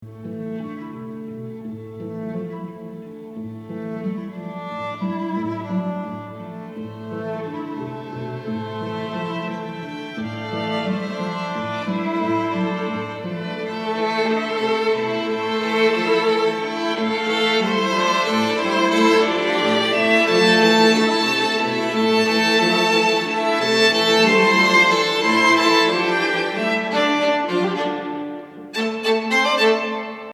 kör